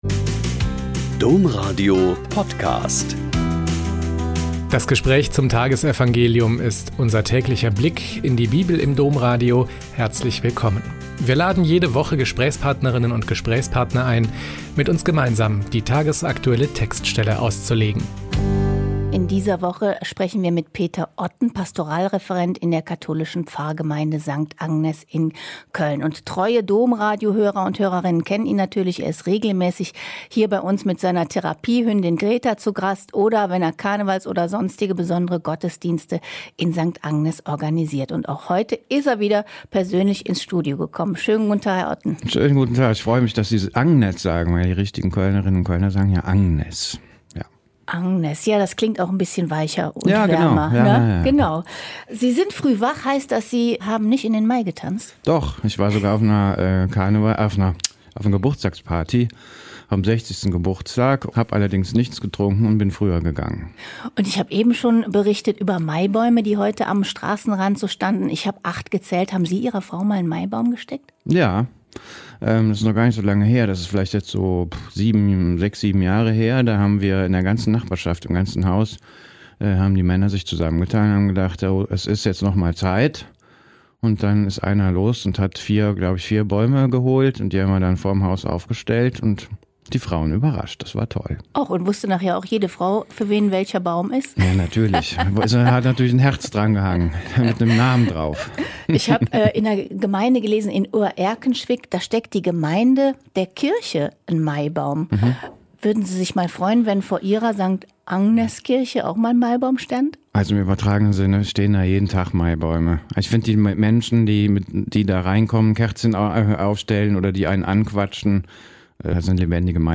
Joh 15,1-8 - Gespräch